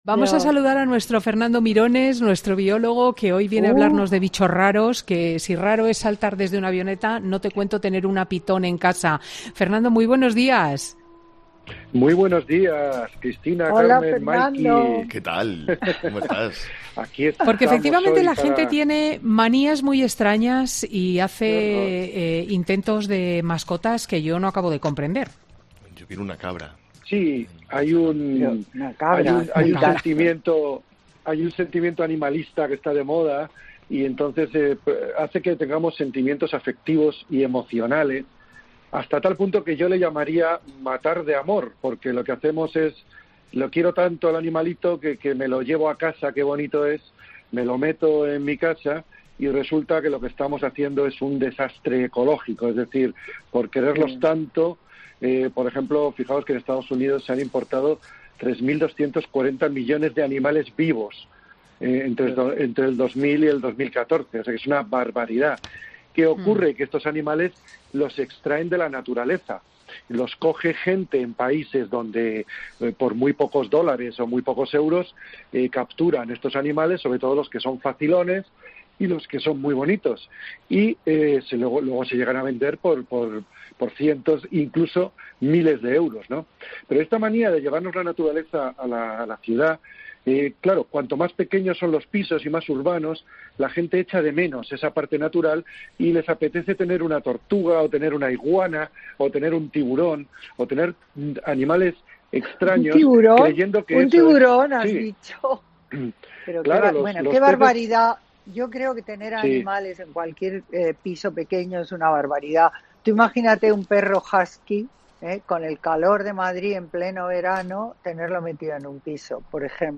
Es lo que nos ha explicado Fernando López-Mirones, divulgador científico y biólogo, que da todas las claves de zoología y evolución en Fin de Semana.